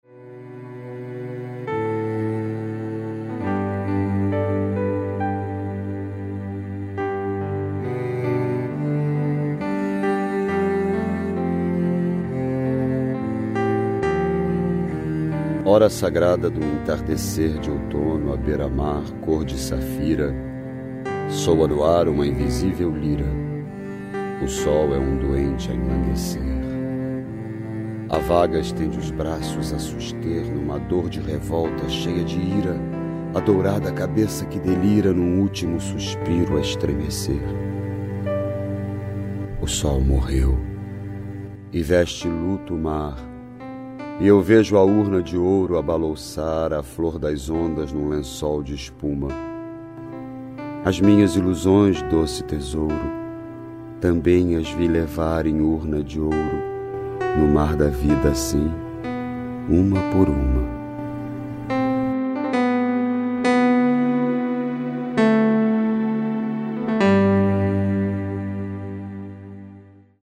44 As minhas ilusões (Florbela Espanca - declamação: Miguel Falabella)